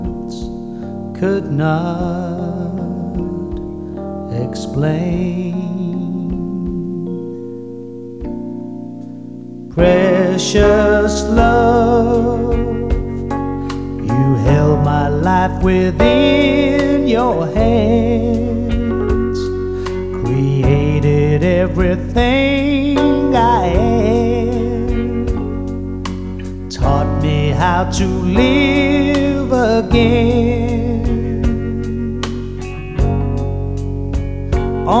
Жанр: Поп музыка / Рок
Pop, Adult Contemporary, Pop, Rock